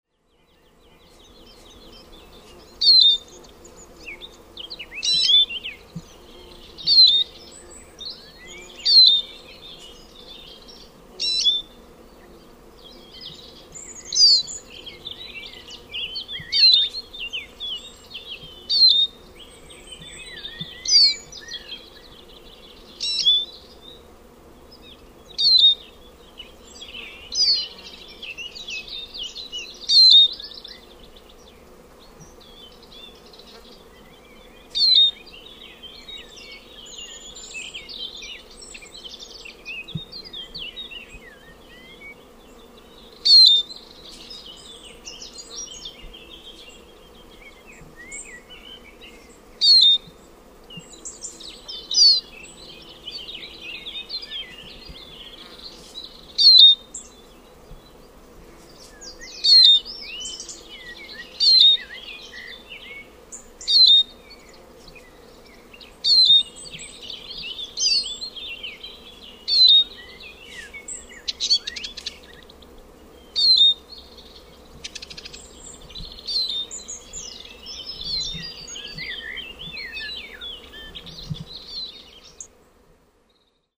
SPINUS SPINUS
Eurasian Siskin utters thin, plaintive “DLU-ee” as usual call, often in flight. When they are feeding in groups, they give low, dry twittering “tetetet”. When it alights among other siskins, it utters harsh, raspy “chrrr-chrrr”.
Song is fairly melodious, a sweet undulating series of twittering phrases, but ending in a raspy “kreee”. This song is given during the flight displays or from tree tops.
SPINUS-SPINUS.mp3